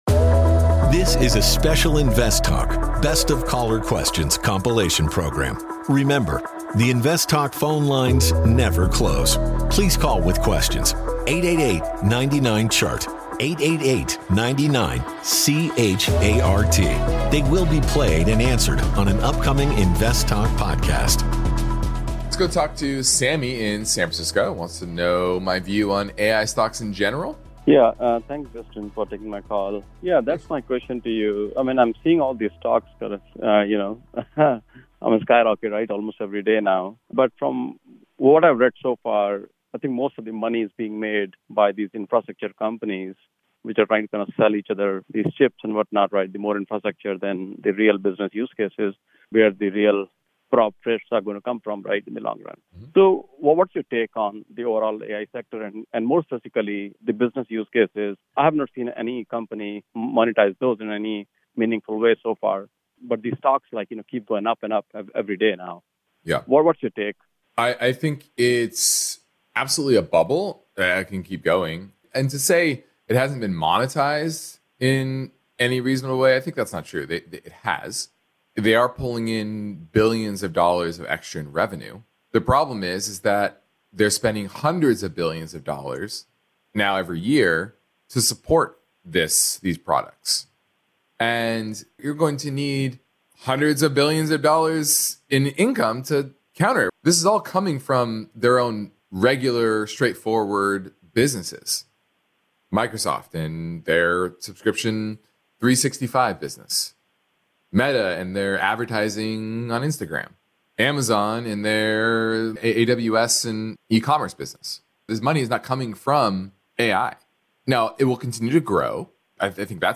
Black Friday - Best of Caller Questions